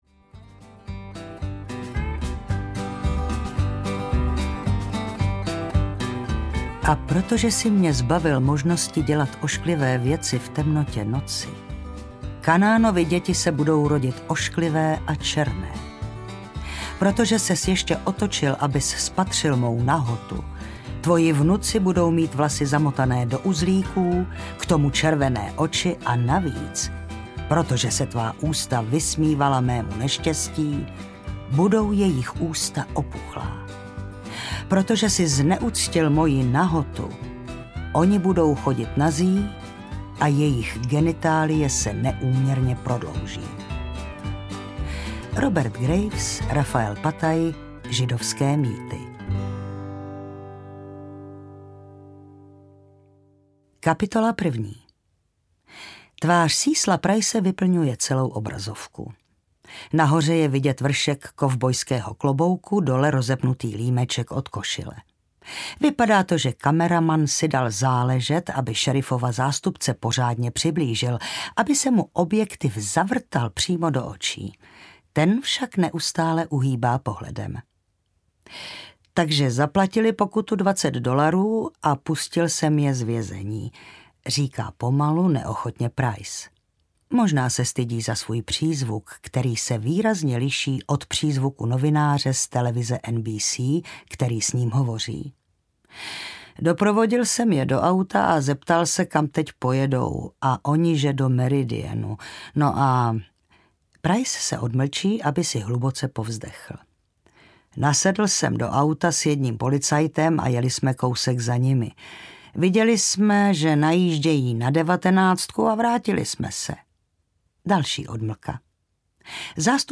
Ku-klux-klan, tady bydlí láska audiokniha
Ukázka z knihy
ku-klux-klan-tady-bydli-laska-audiokniha